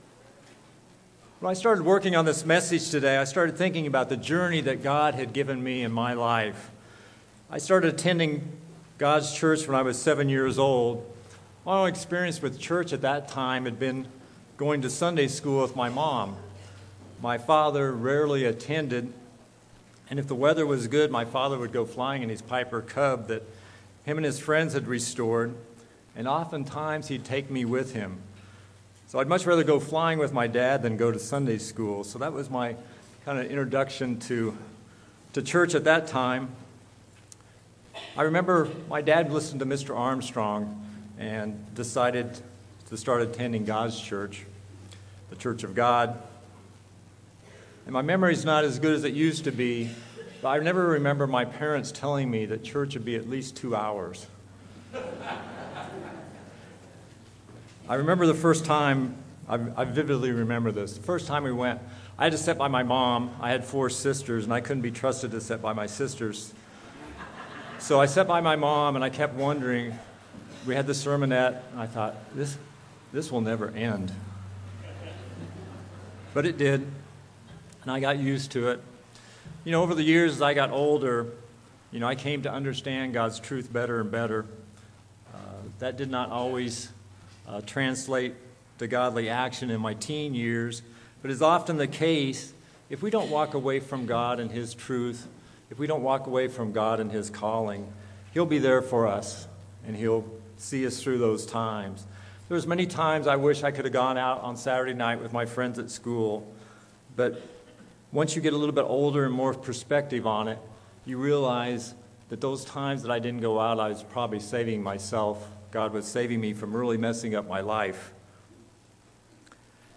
This sermon was given at the Wisconsin Dells, Wisconsin 2019 Feast site.